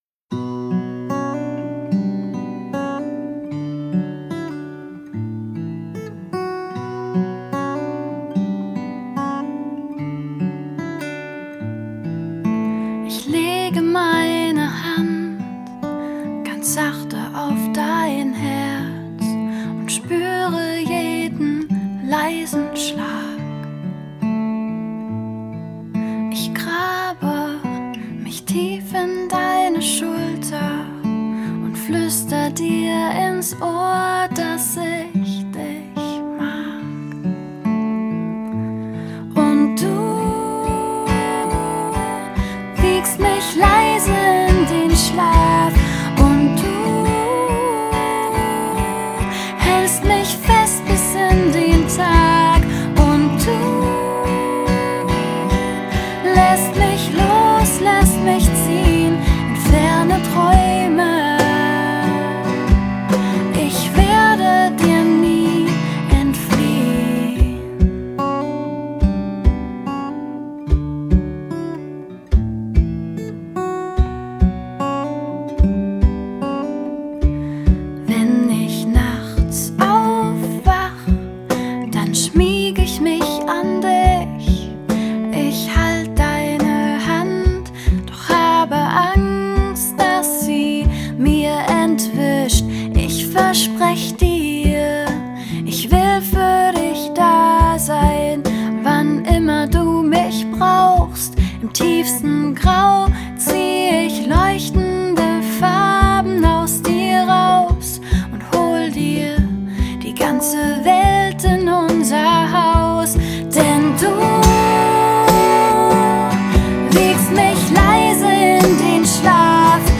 Singer Songwriter Pop